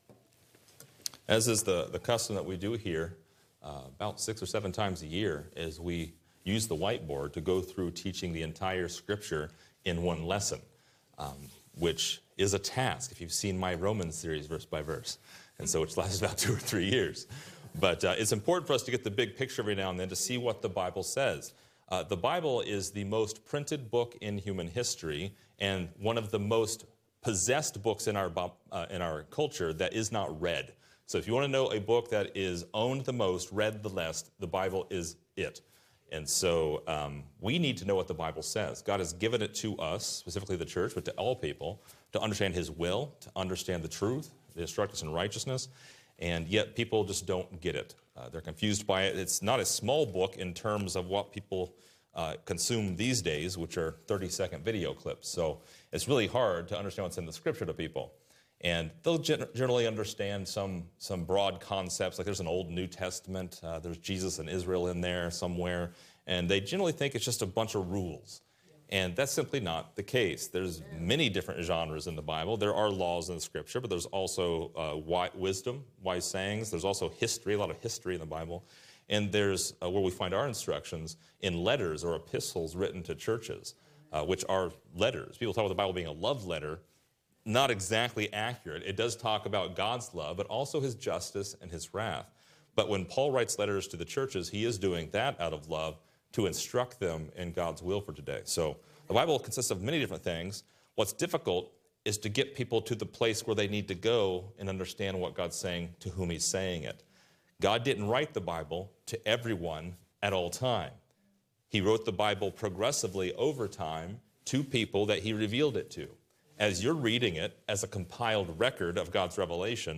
About four times a year, we teach a chart lesson at Grace Ambassadors. These lessons are an opportunity to see the Bible in its entirety while identifying key dispensational differences according to a Mid-Acts Pauline perspective.